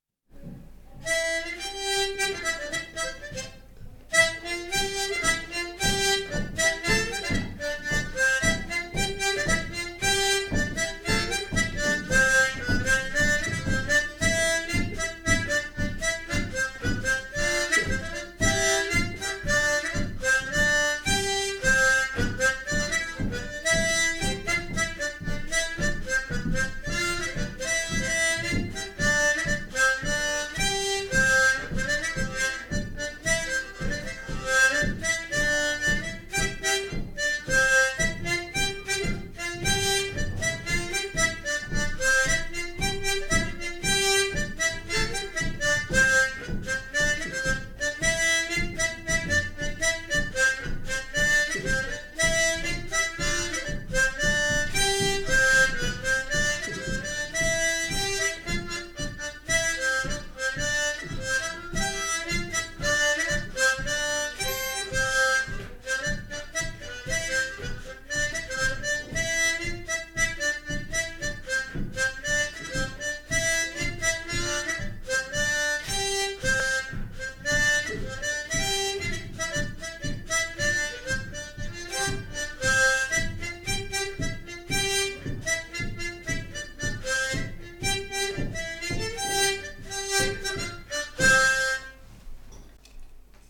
Polka
Genre : morceau instrumental
Instrument de musique : harmonica
Danse : scottish